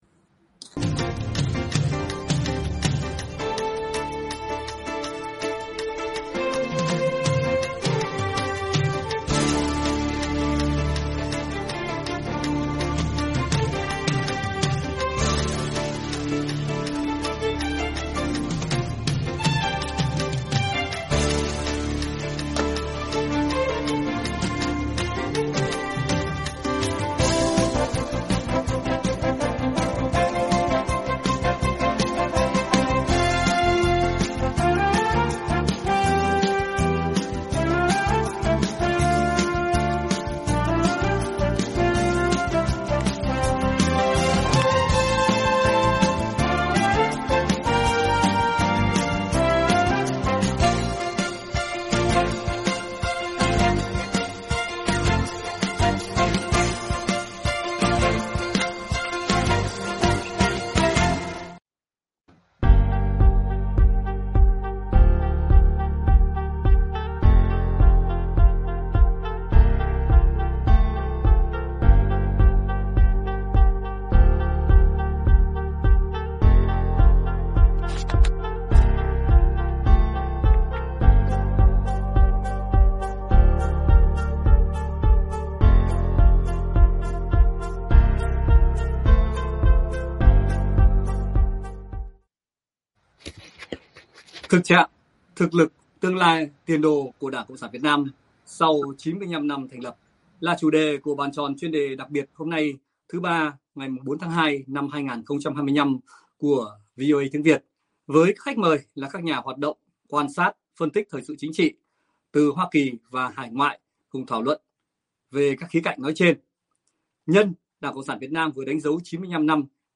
Bàn Tròn Chuyên Đề đặc biệt thứ Ba ngày 04/02/2025 của VOA Tiếng Việt với các khách mời là các nhà hoạt động, quan sát, phân tích thời sự chính trị từ Hoa Kỳ và hải ngoại